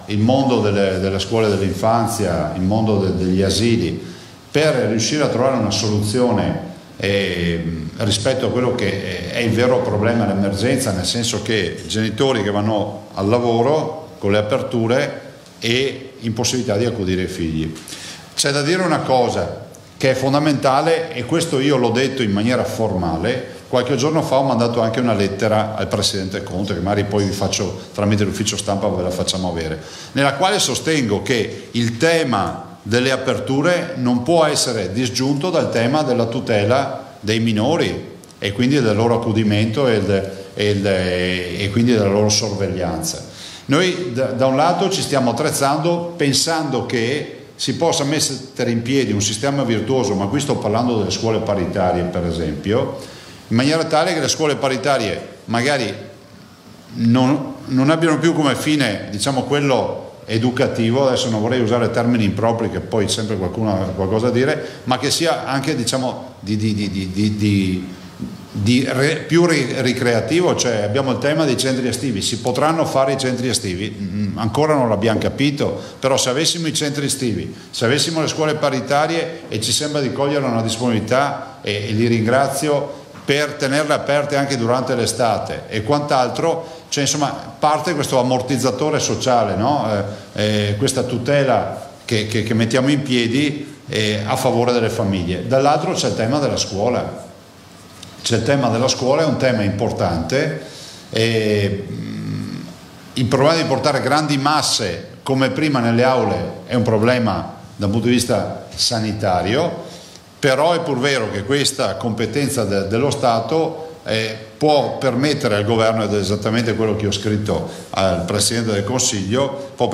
ZAIA E LANZARIN DALLA CONFERENZA STAMPA DI OGGI
GLI INTERVENTI PRINCIPALI DI LUCA ZAIA